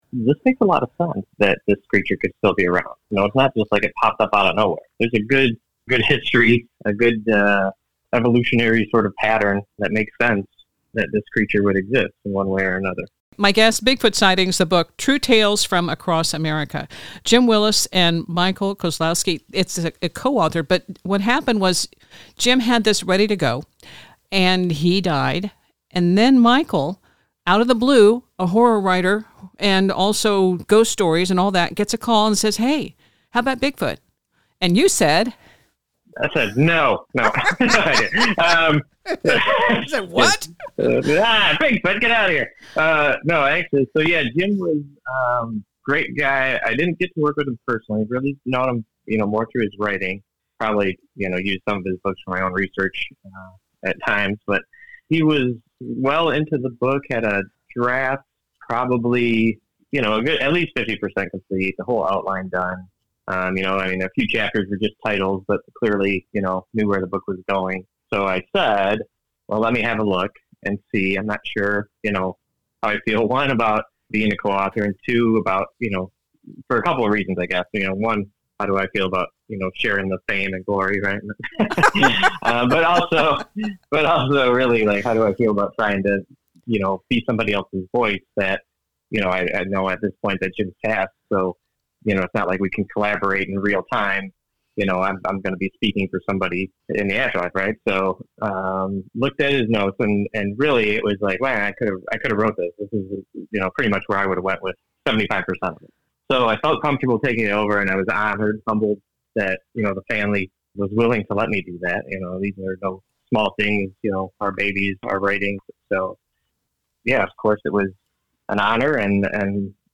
and in this interview